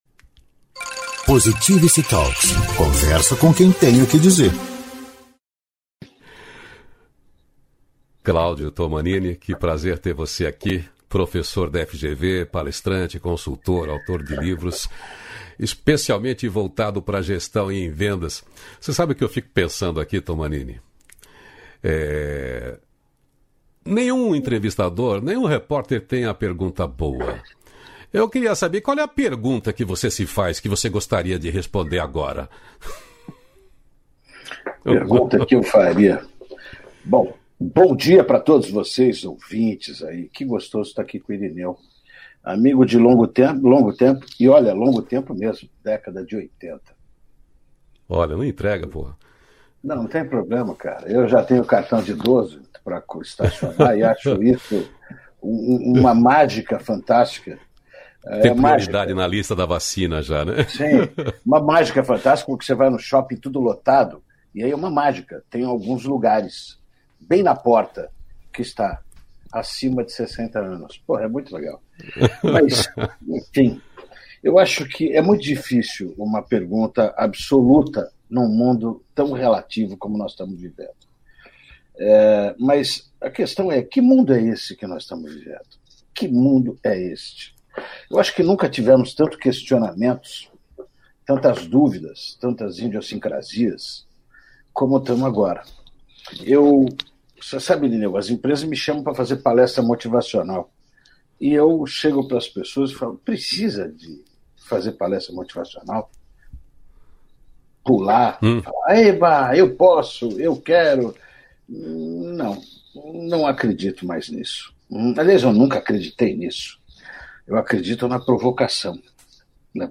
Diálogo Nutritivo